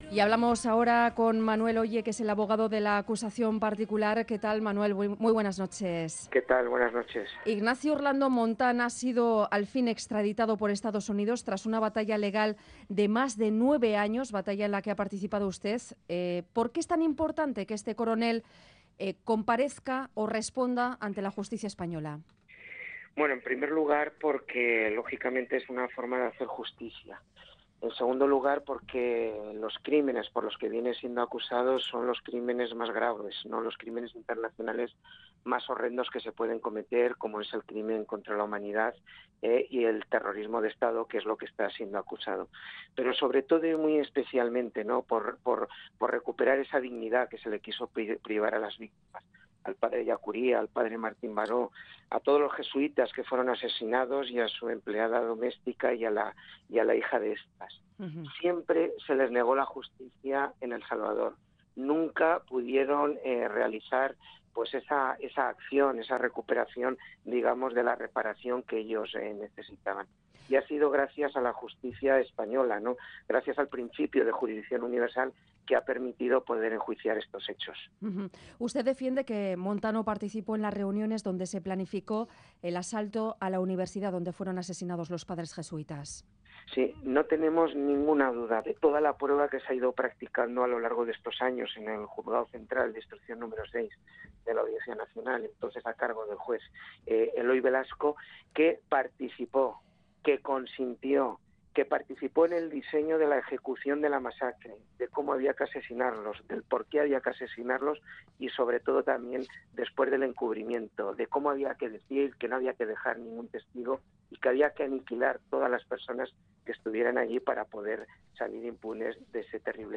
Audio: Las autoridades de Estados Unidos han entregado este miércoles a España al exviceministro de Defensa de El Salvador Inocente Orlando. Entrevista